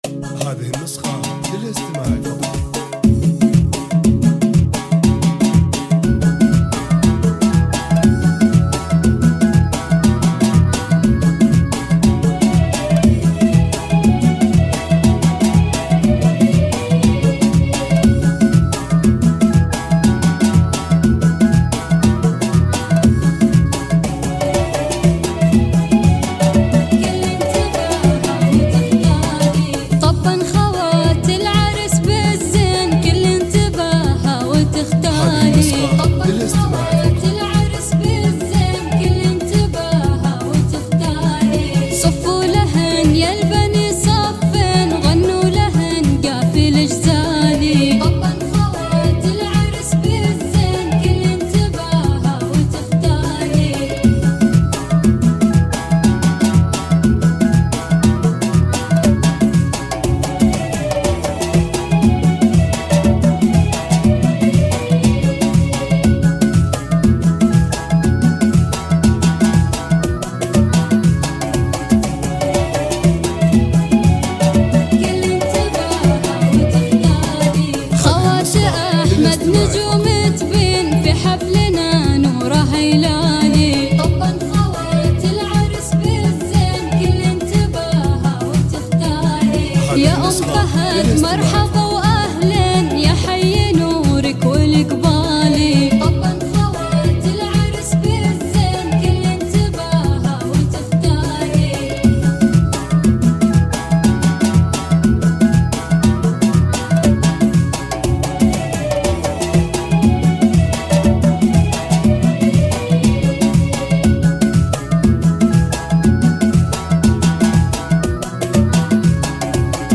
زفات موسيقى – زفات كوشة